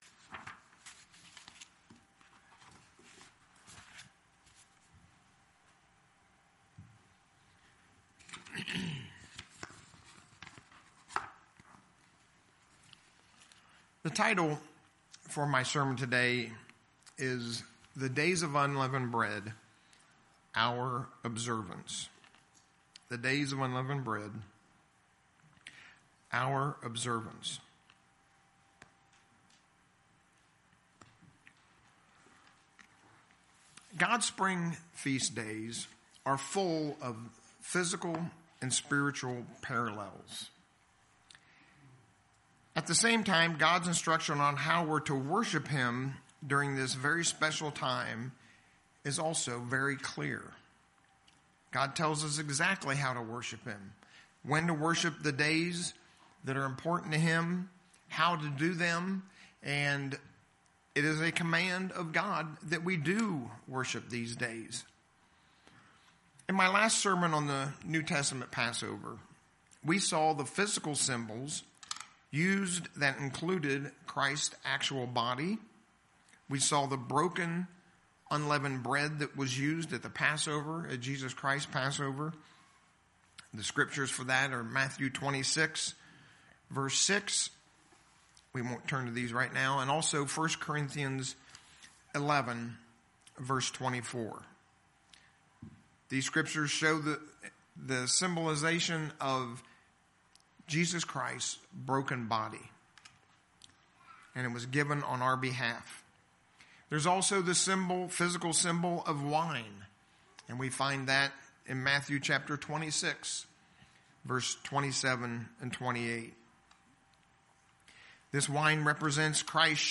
All of God's feast days point to His plan of salvation for mankind and this would include the feast of the Days of Unleavened Bread. In the sermon today we'll review the meaning and the proper observance of the Days of Unleavened Bread.